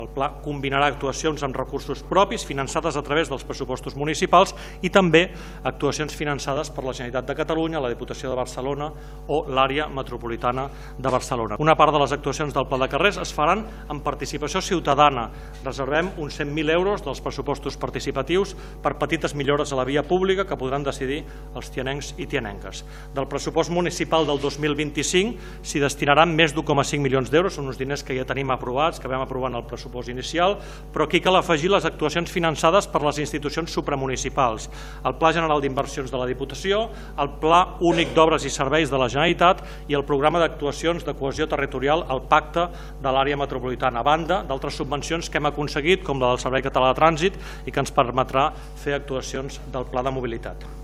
El batlle va explicar que el Pla de carrers és un “pla viu”, obert a la resta de grups polítics per marcar prioritats i afegir noves actuacions.